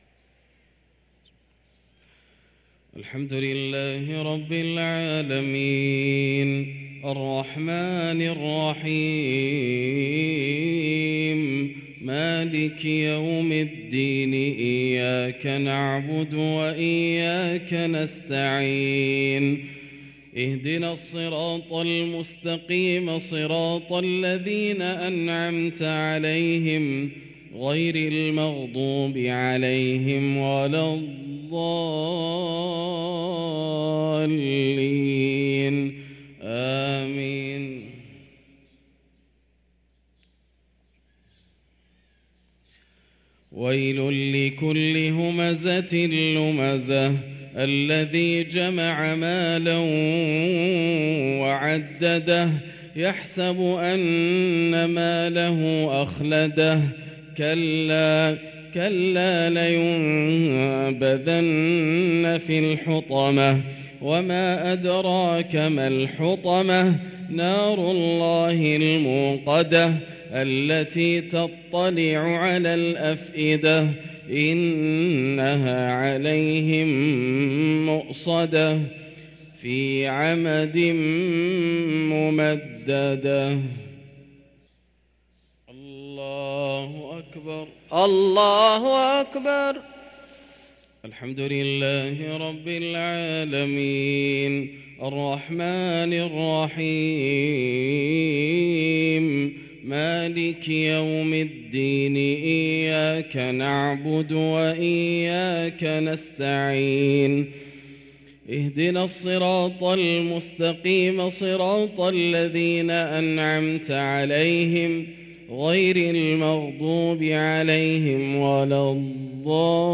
صلاة المغرب للقارئ ياسر الدوسري 3 رجب 1444 هـ